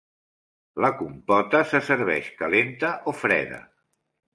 Pronounced as (IPA) [ˈfɾɛ.ðə]